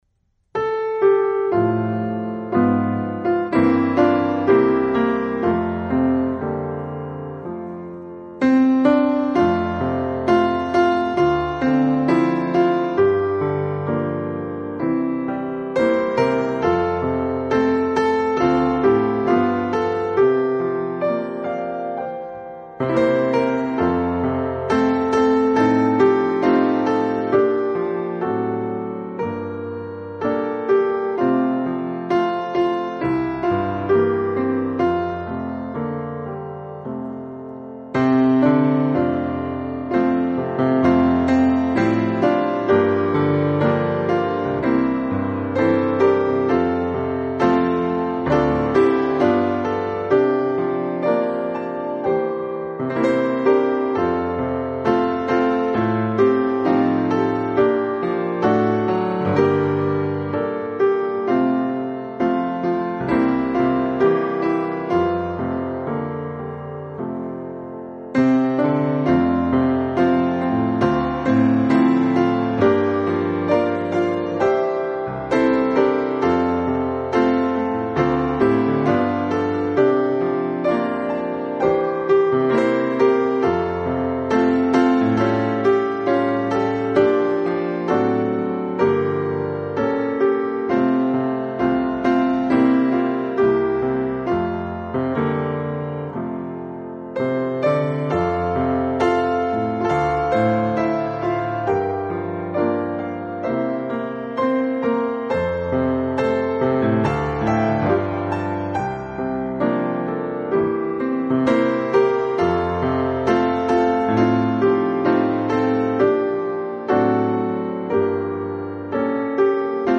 The version here is simplified in both text and tune.
Let this quiet spiritual remind us in those times what awaits us in the wonder of heaven and that there is no need to fear our “unseen enemy,” death.